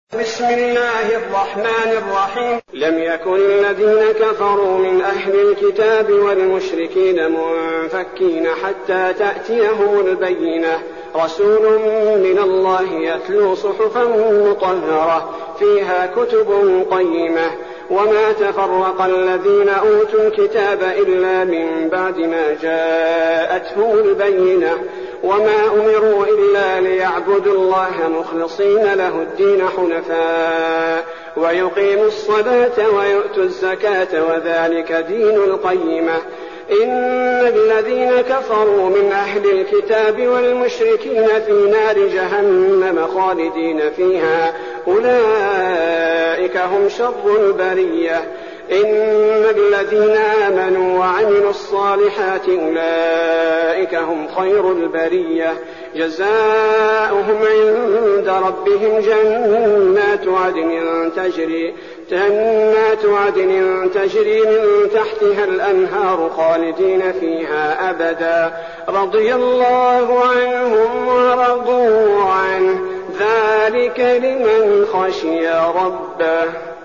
المكان: المسجد النبوي الشيخ: فضيلة الشيخ عبدالباري الثبيتي فضيلة الشيخ عبدالباري الثبيتي البينة